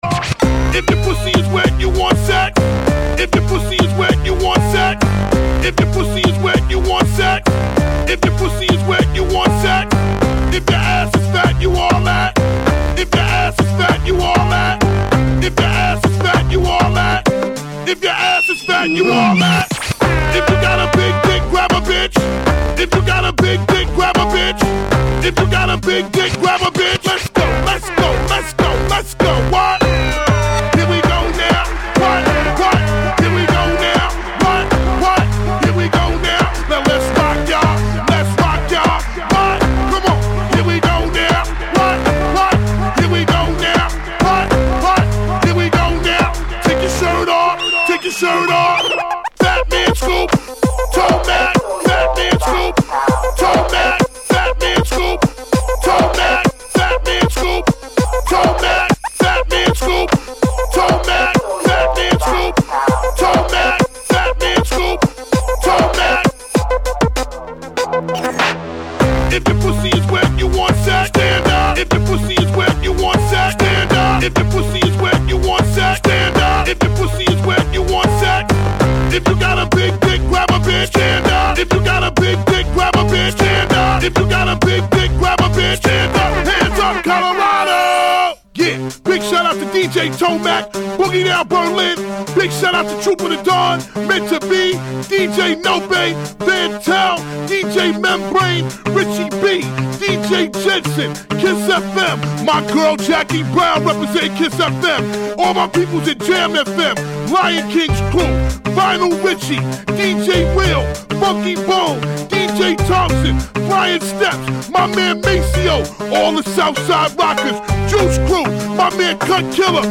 To apologize, I am posting the greatest love song ever for yor listening pleasure.